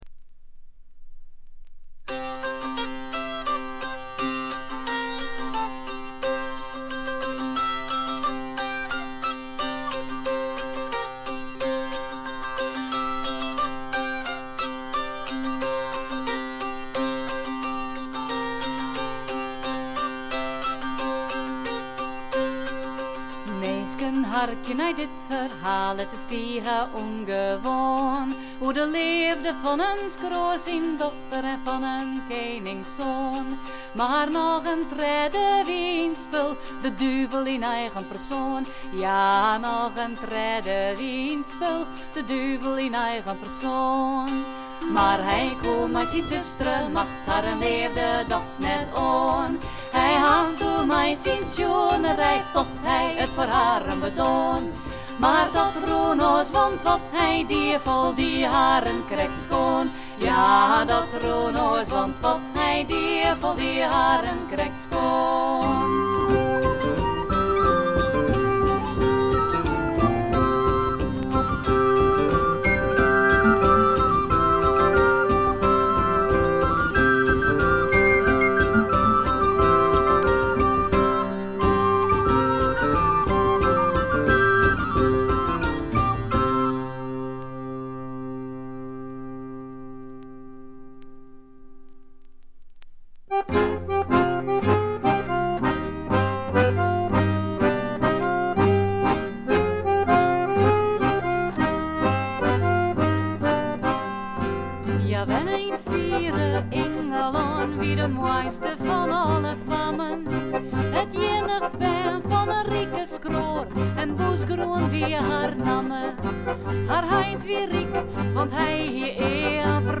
チリプチ少し。散発的なプツ音少し。
ダッチフォークの名バンド
アシッド感がただようところも点数高いです。
本作はまた組曲仕立ての大変凝ったもので聴き応え十分。
試聴曲は現品からの取り込み音源です。